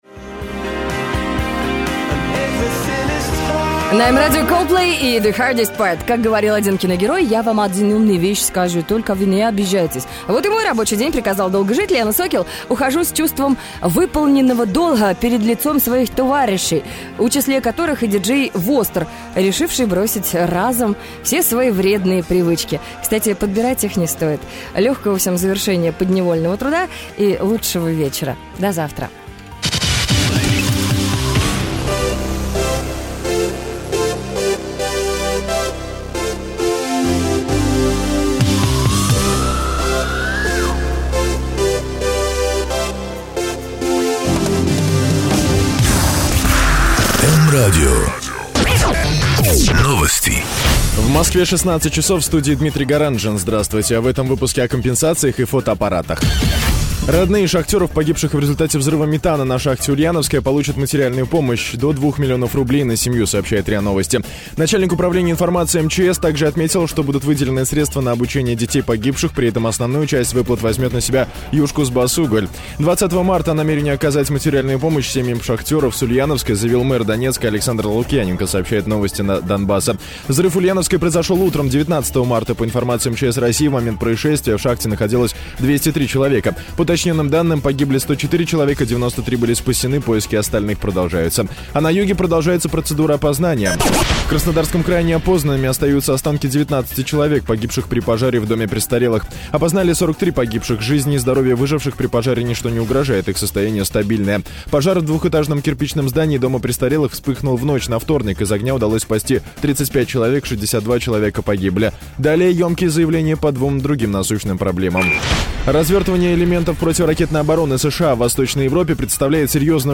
Начало часа, новости, погода (М-Радио, 20.03.2007)